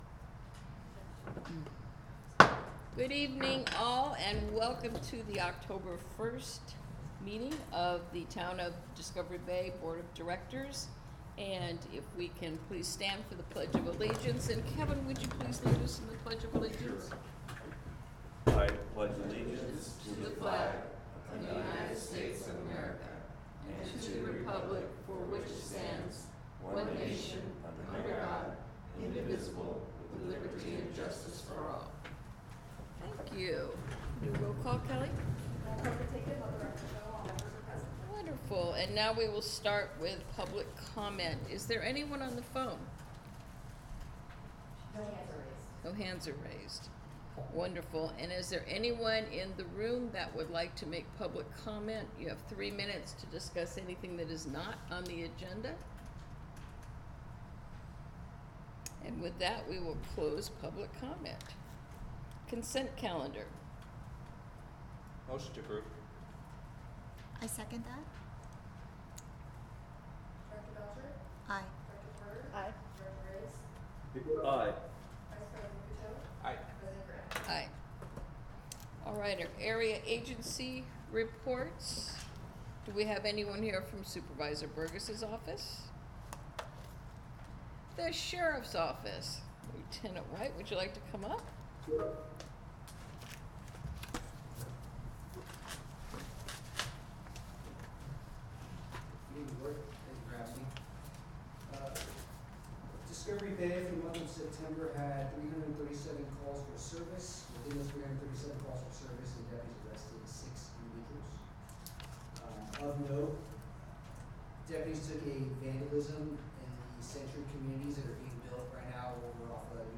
Board of Directors Meetings
The Town of Discovery Bay CSD meets twice monthly on the first and third Wednesday of each month at 7:00 p.m. at the Community Center located at 1601…